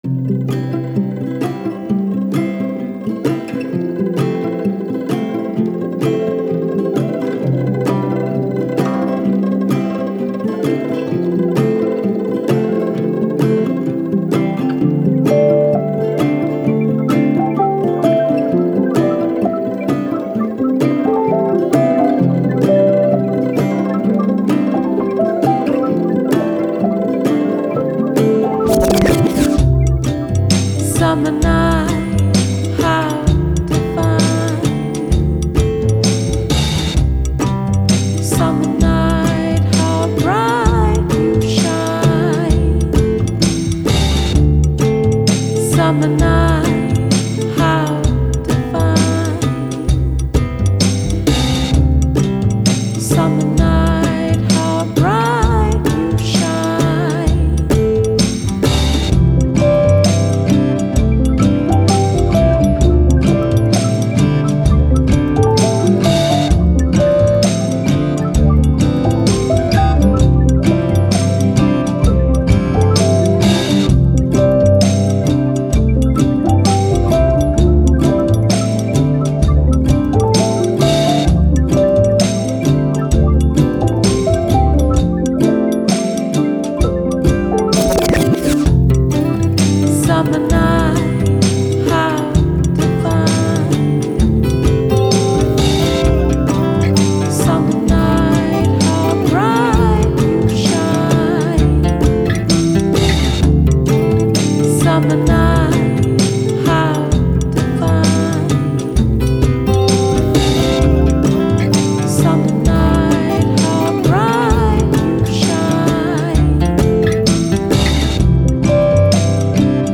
Pop, Downtempo, Playful, Quirky, Vocal